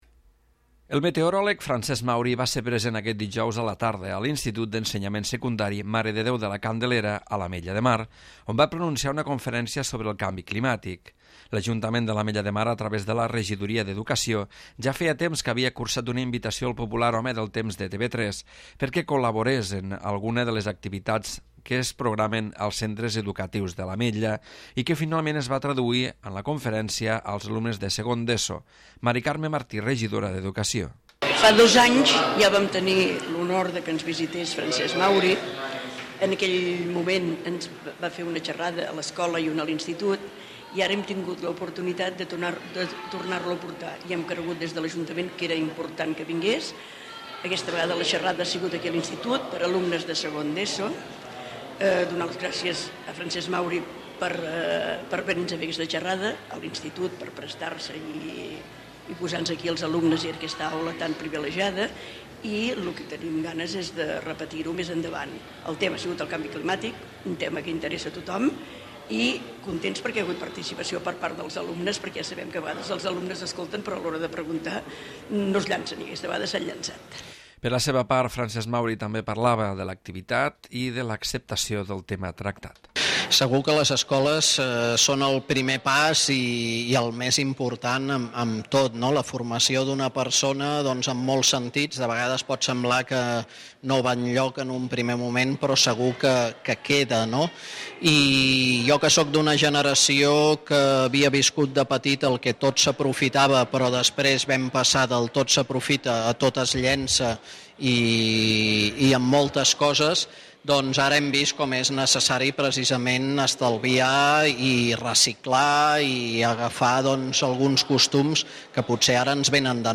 El meteoròleg Francesc Mauri va ser present aquest dijous a la tarda a l'Institut d'Ensenyament Secundari Mare de Déu de la Candelera on va pronunciar una conferència sobre el canvi climàtic.